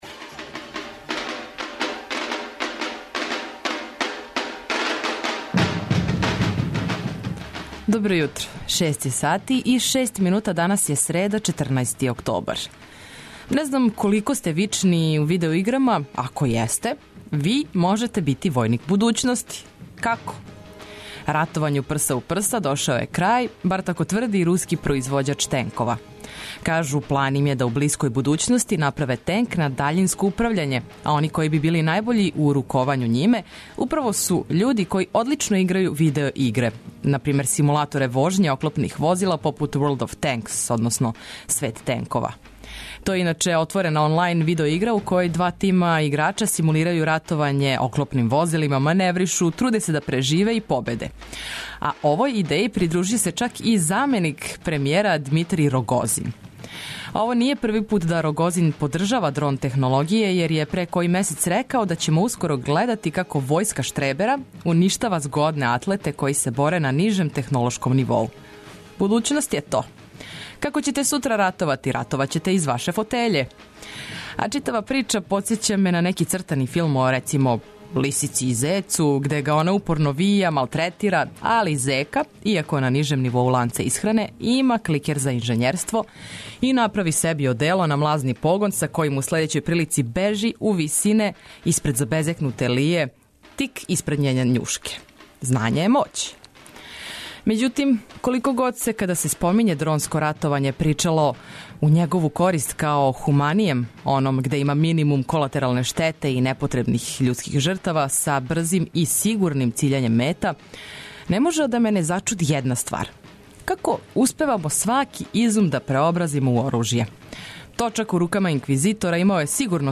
Од 6 ујутру будимо вас омиљеним песмама, корисним и забавним причама, прелиставамо страну и домаћу штампу, пратимо актуелности и разговарамо о њима.
Нека буде весело, питко и лагано јутро уз Двестадвојку!